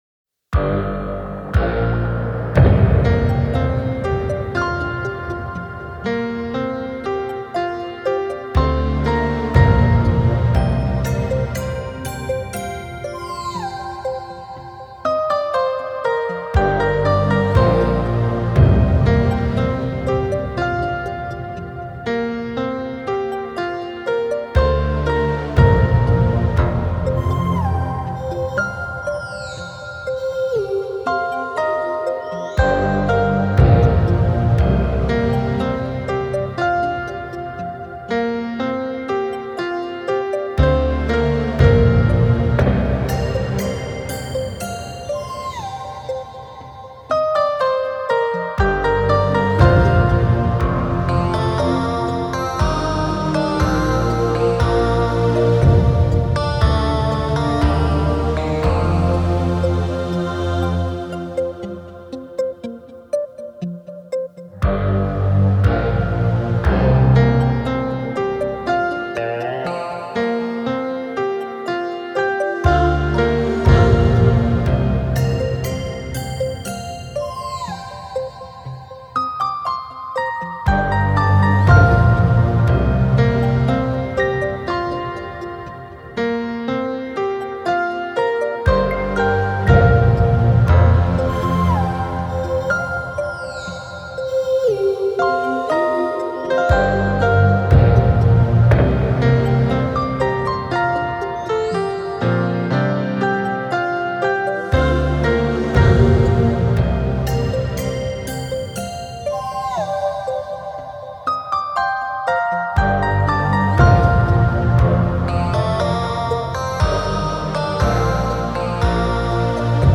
keyboard player